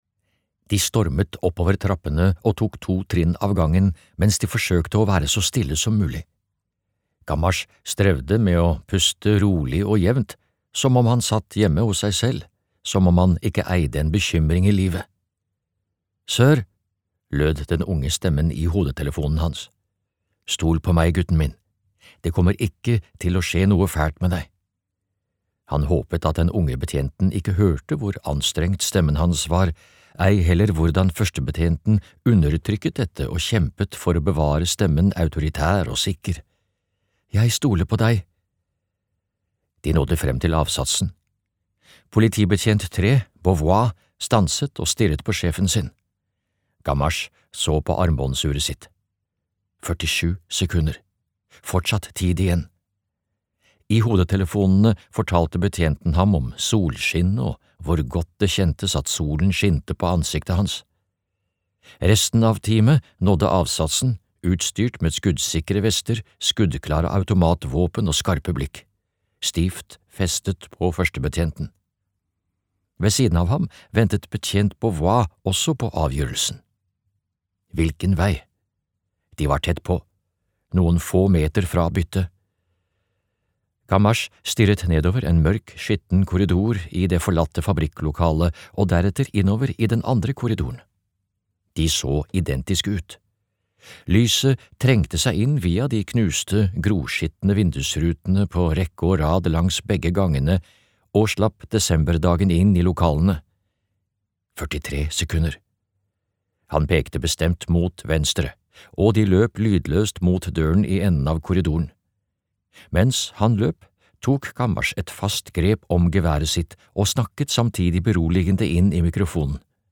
Begrav dine døde (lydbok) av Louise Penny